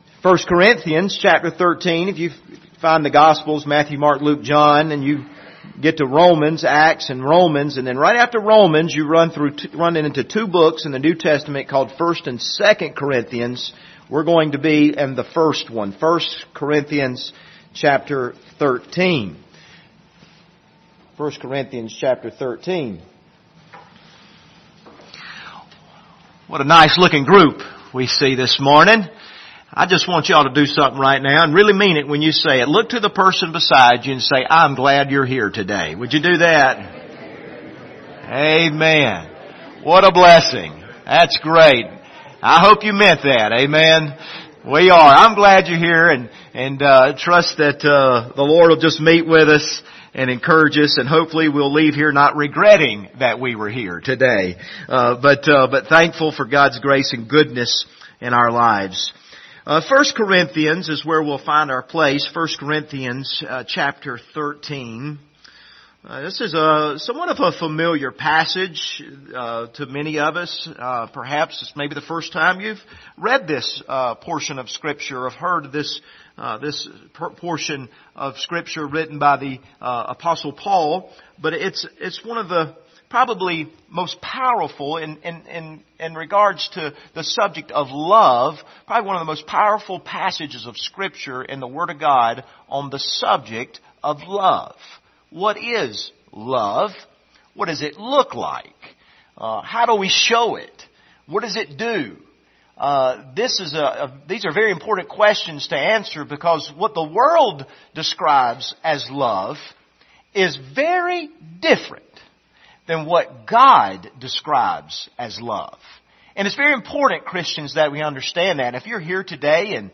Passage: 1 Corinthians 13 Service Type: Sunday Morning View the video on Facebook Topics